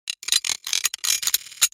جلوه های صوتی
دانلود صدای ربات 17 از ساعد نیوز با لینک مستقیم و کیفیت بالا